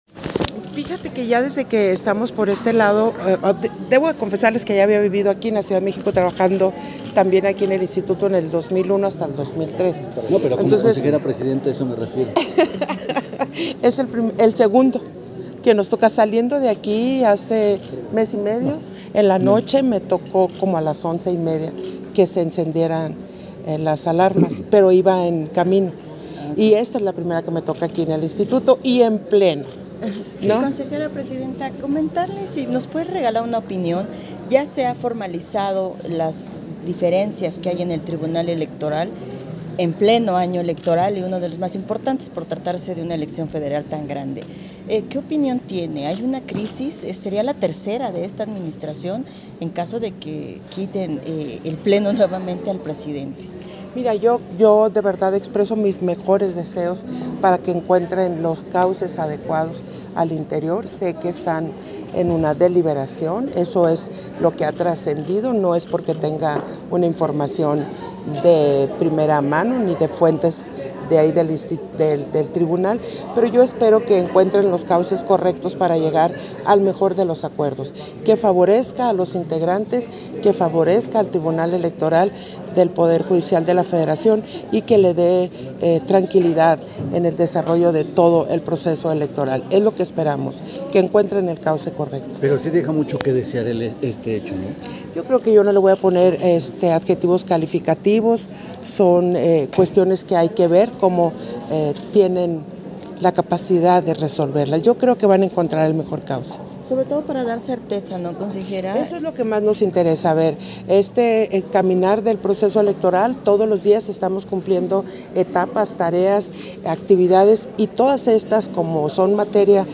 071223_AUDIO_ENTREVISTA_CONSEJERA-PDTA.-TADDEI-SESIÓN-EXT.
Versión estenográfica de la entrevista que concedió Guadalupe Taddei, a diversos medios de comunicación, posterior a la Sesión Extraordinaria del Consejo General